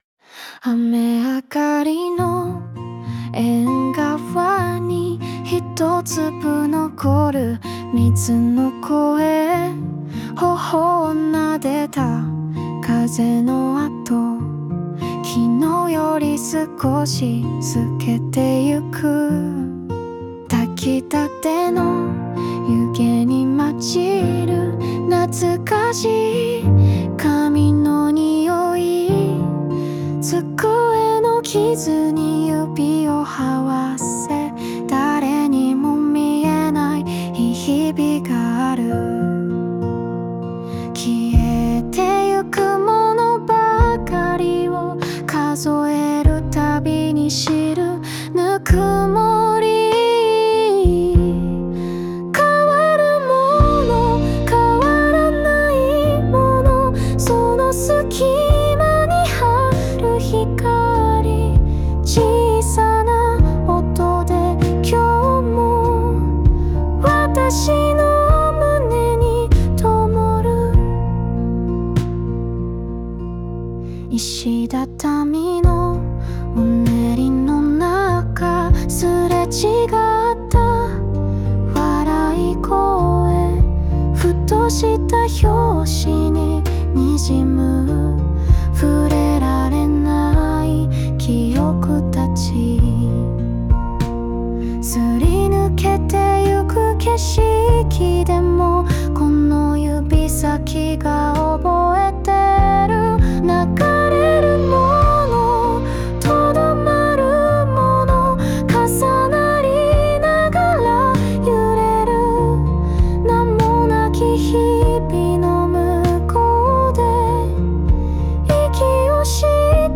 邦楽女性ボーカル著作権フリーBGM ボーカル
女性ボーカル（邦楽・日本語）曲です。